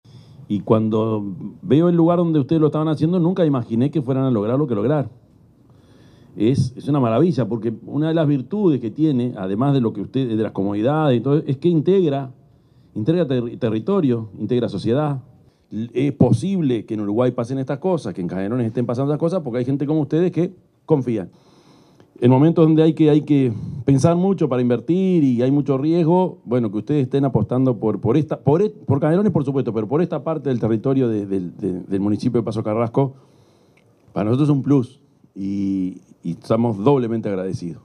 discurso_intendente_orsi.mp3